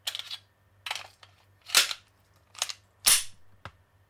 Gun cock
描述：Hand gun being cocked fast.Recorded from the side of the gun.Recorded with a ZOOM H6 recorder without a foam windscreen.Recorded for a school project for a sound library.
标签： GUN OWI COCK
声道立体声